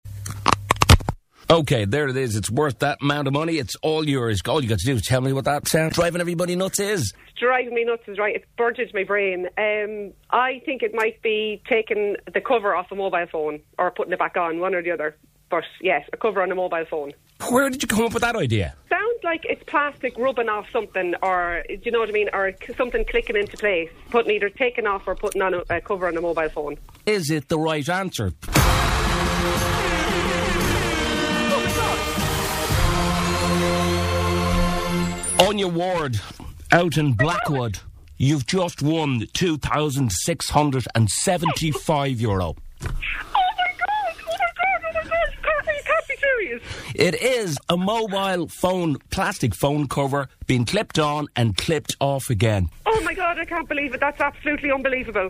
Yes, that satisfying pop.
The sound? A phone case being clipped on to a phone.